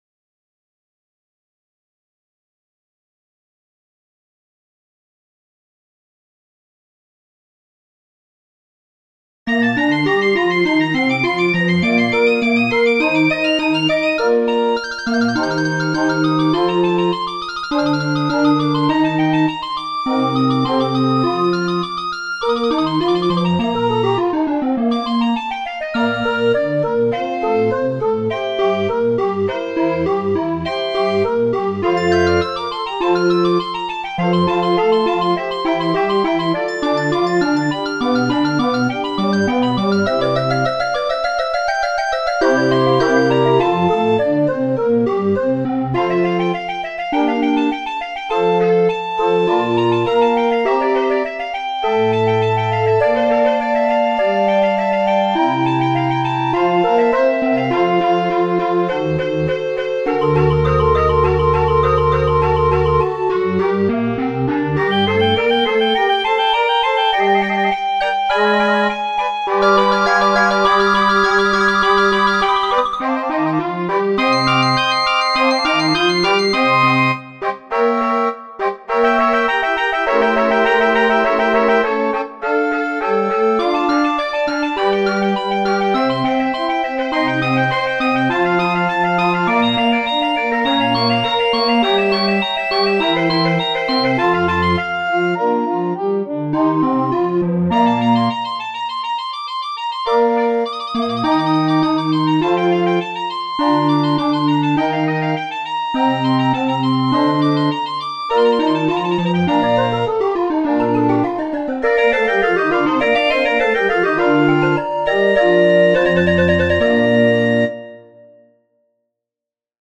Below you will find all the Mp 3-files of the basso continuo & the PDF-files of the sheet-music for mandolin / flute
LOVELY SOUNDS FROM THE BAROQUE ERA
Music for flauto dolce / mandolino and organetto by Vivaldi, Bach, Telemann and others (Please read the score first, before you start)
TELEMANN trio B dur sats 2 FLAUTO MAND ORGAN 102 EDITION stark bas MP3.mp3